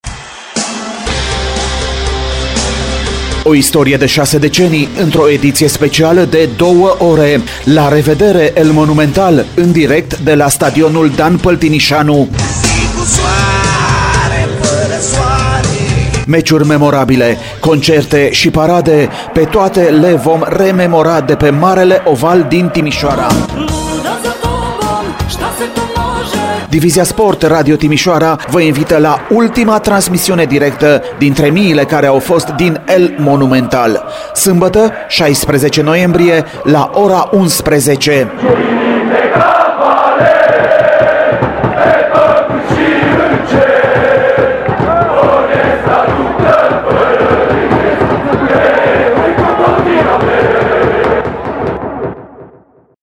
Promo-LA-REVEDERE-EL-MONUMENTAL.mp3